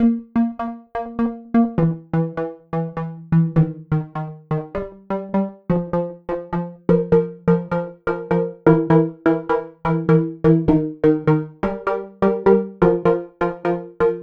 Track 10 - Arp Syncopation 01.wav